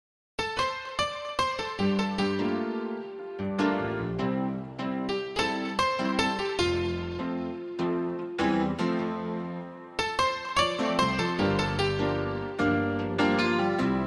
Light jazz listen new sound effects free download